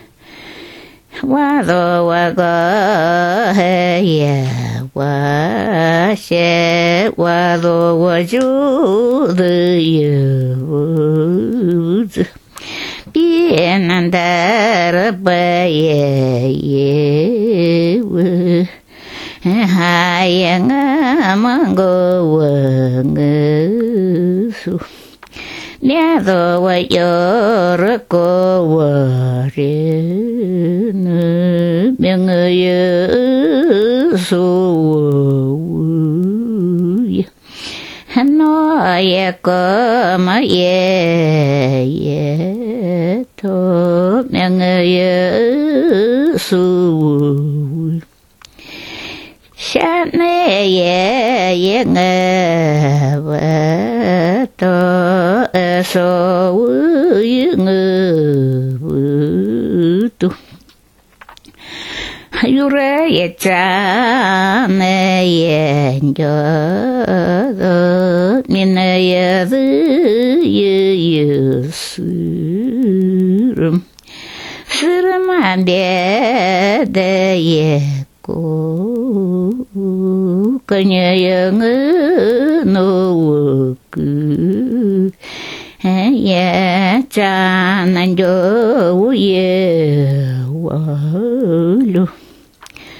Фрагмент эпической песни жанра сюдбăбц . Эпическая песня исполняется на канинском (крайнезападном) диалекте тундрового ненецкого языка.